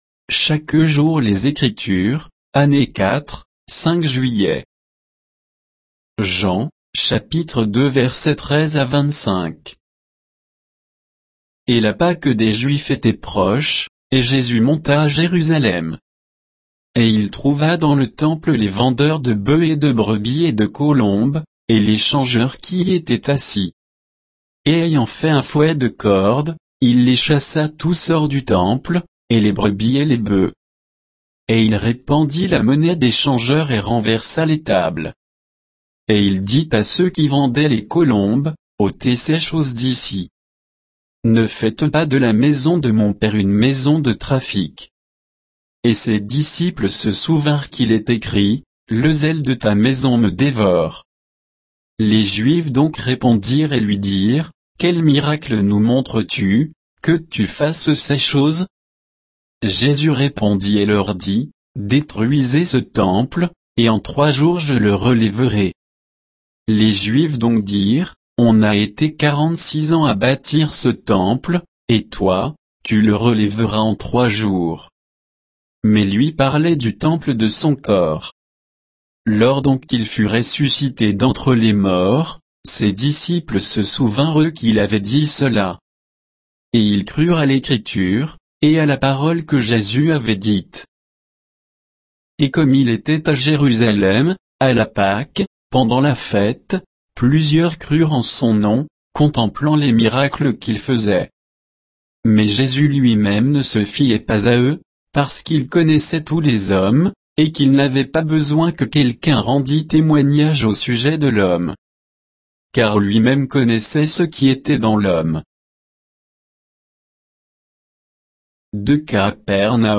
Méditation quoditienne de Chaque jour les Écritures sur Jean 2